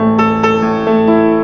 Index of /90_sSampleCDs/USB Soundscan vol.03 - Pure Electro [AKAI] 1CD/Partition E/04-PIANOS